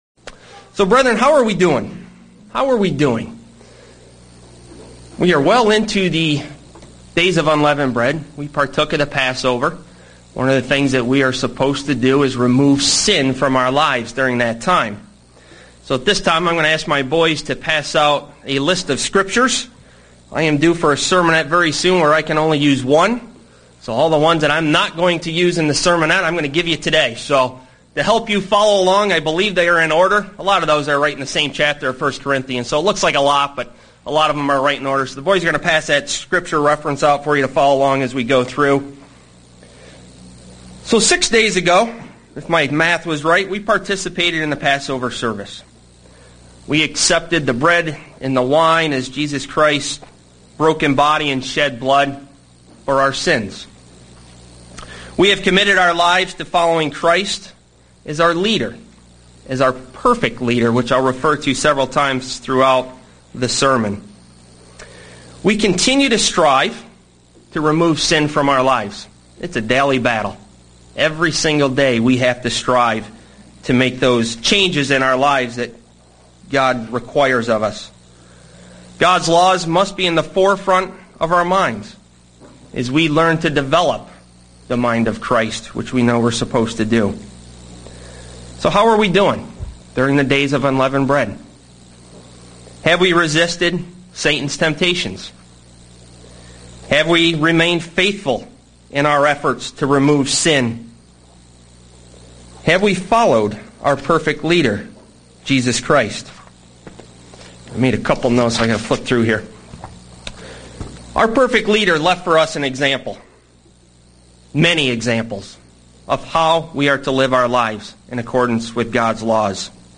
Given in Buffalo, NY
UCG Sermon Studying the bible?